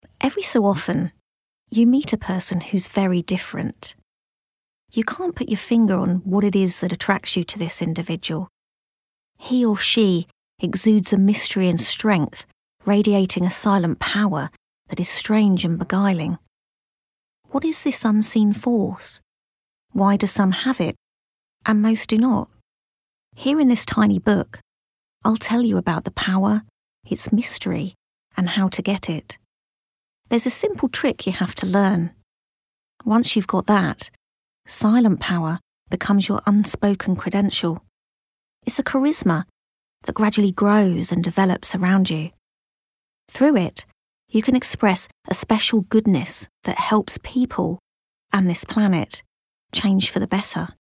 Female
Yng Adult (18-29), Adult (30-50)
Her calm, friendly, and articulate tone is easily understood by global audiences, making her ideal for corporate narration, e-learning, and explainer content.
Audiobooks
Audiobook Example - Self Help
Words that describe my voice are Calm, Authoritative, Conversational.